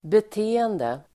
Uttal: [bet'e:ende]